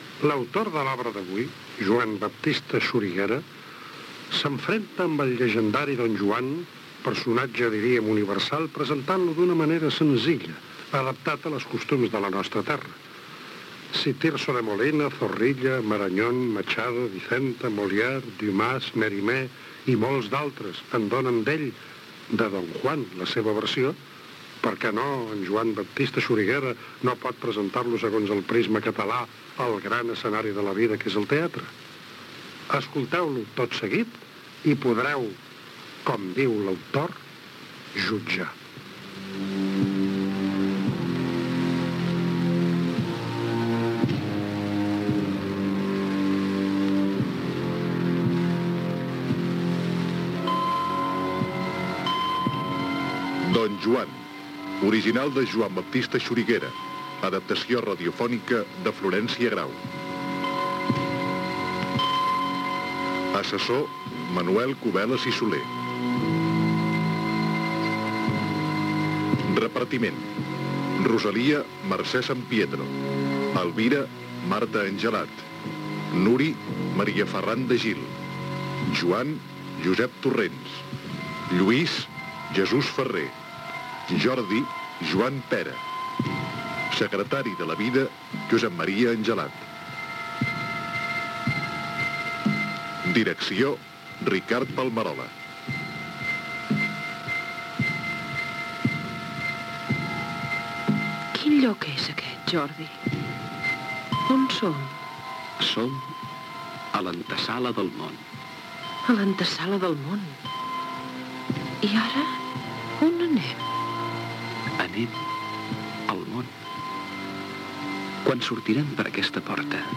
Adaptació radiofònica
Gènere radiofònic Ficció